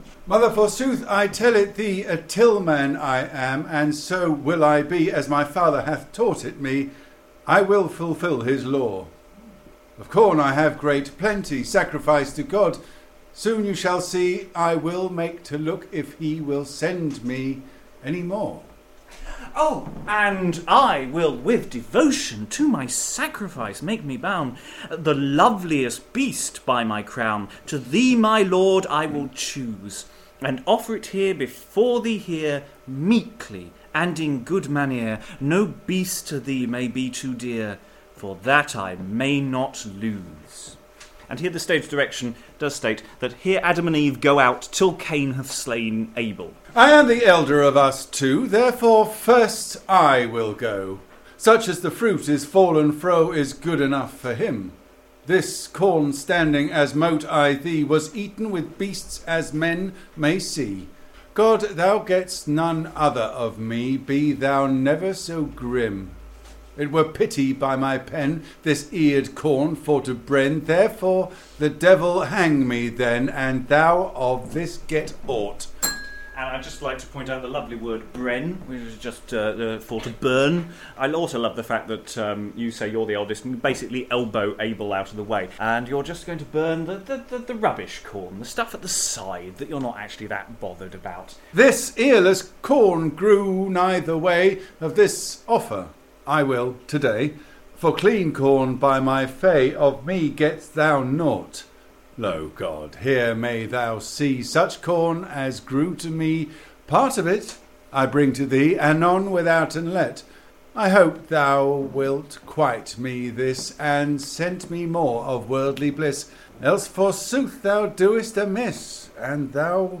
Facebook Twitter Headliner Embed Embed Code See more options Last part of the second pageant of the Chester cycle - closing the story of Cain and Abel. Exploring the Chester Mystery Plays is a series of live streamed events where the Chester plays are taken apart with readers and commentary. Rough round the edges, these edited versions of these events are now being posted online.